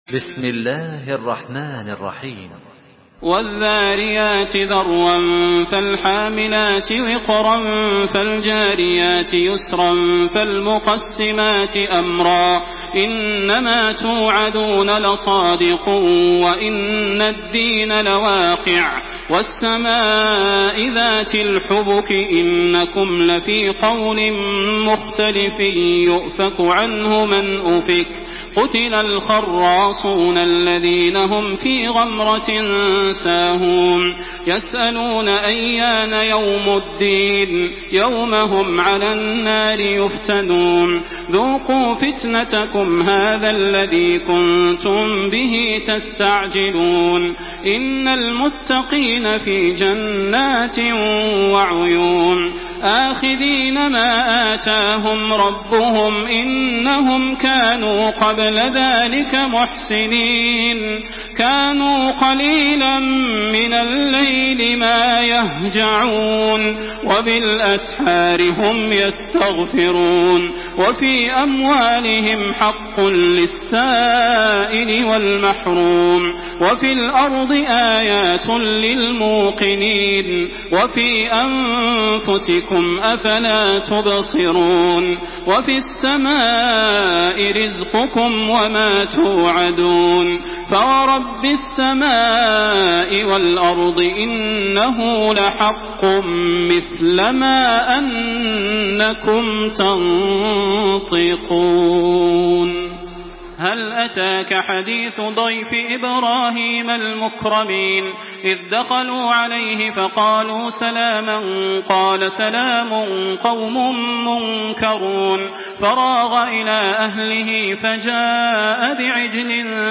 Surah Zariyat MP3 by Makkah Taraweeh 1427 in Hafs An Asim narration.
Murattal